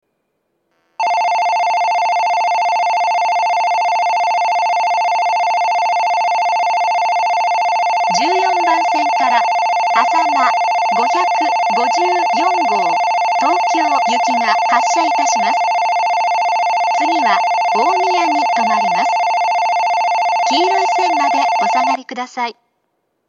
在来線では全ホーム同じ発車メロディーが流れますが、新幹線ホームは全ホーム同じ音色のベルが流れます。
２０１２年頃に放送装置を更新したようで、ベルの音質が向上しています。
１４番線発車ベル 主に北陸新幹線が使用するホームです。
あさま５５４号東京行の放送です。